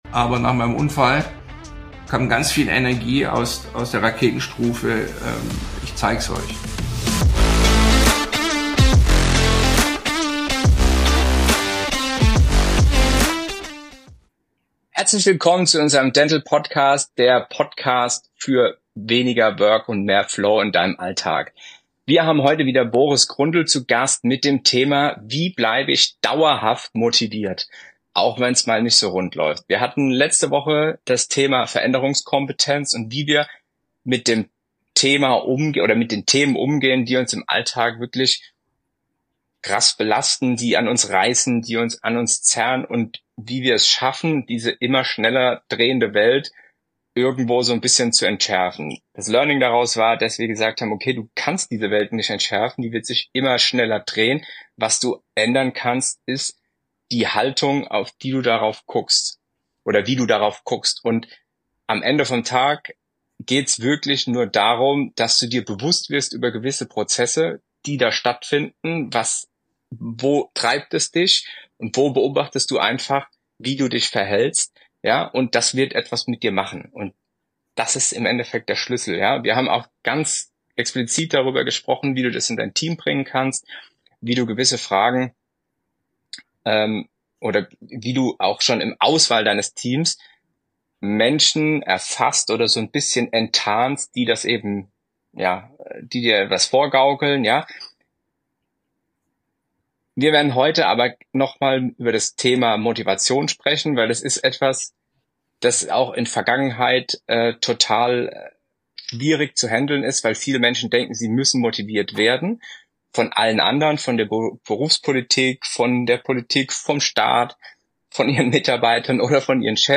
Nr. 24 - Wie du dauerhaft motiviert bleibst, auch in schwierigen Zeiten! - Interview